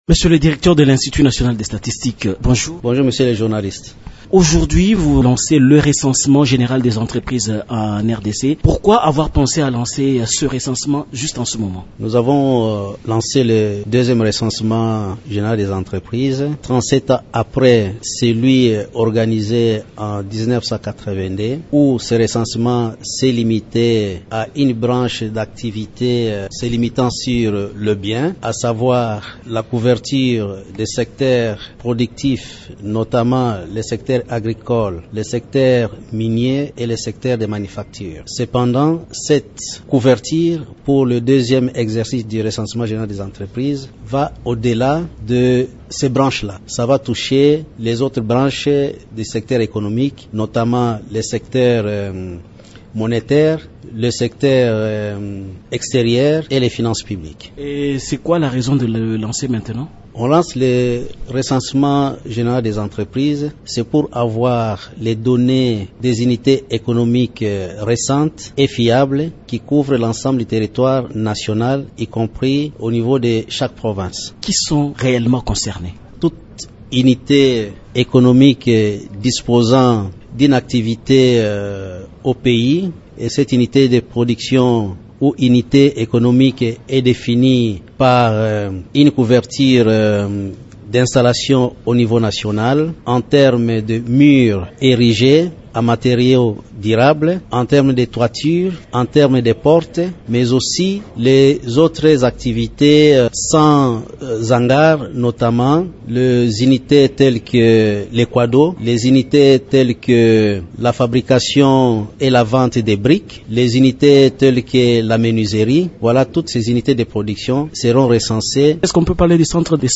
Roger Shulungu Runika est directeur de l’Institut National des Statistiques. Il s’exprime sur le lancement depuis lundi 4 février du processus du recensement général de toutes les entreprises qui exercent en République Démocratique du Congo.